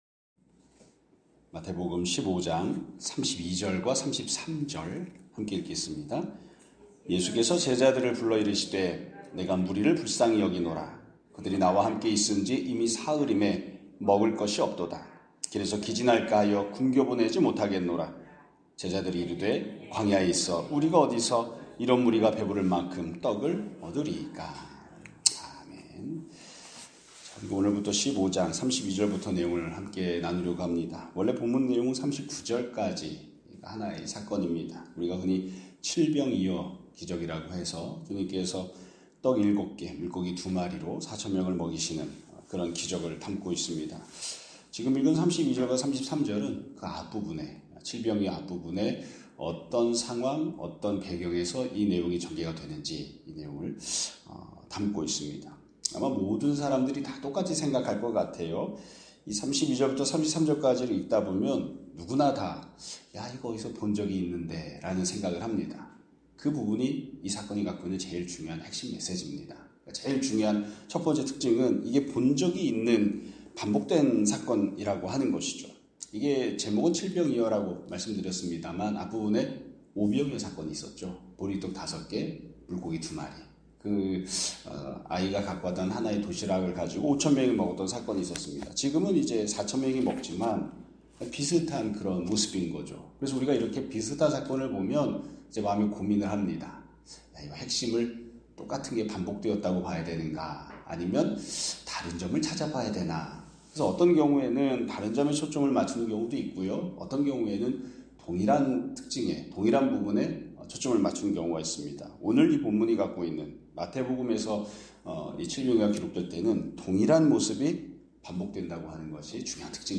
2025년 11월 11일 (화요일) <아침예배> 설교입니다.